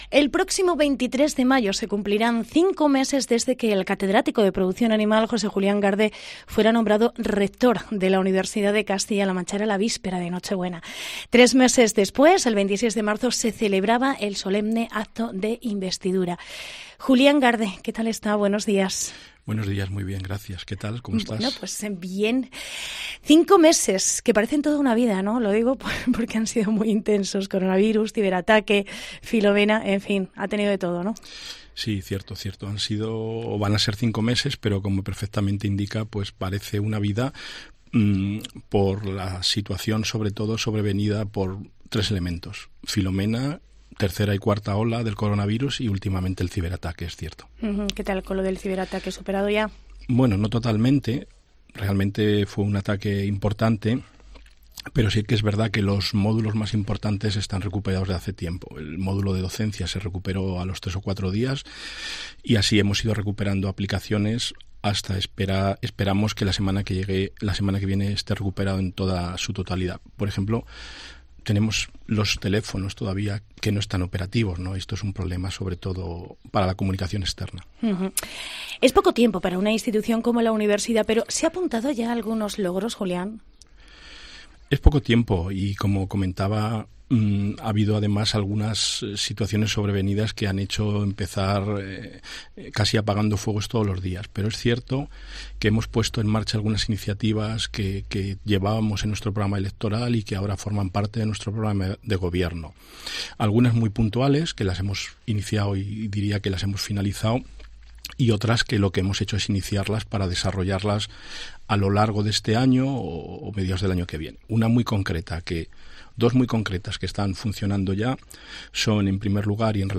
Entrevista Julián Garde, rector UCLM